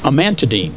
Pronunciation
(a MAN ta deen)